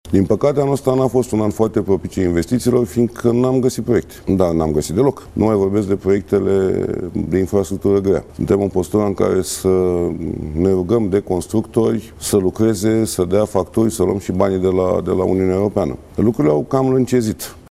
Într-un interviu la Antena 3, premierul a vorbit luni seară nu doar despre intenția de a remania cinci miniștri, ci și despre bugetul pe 2018.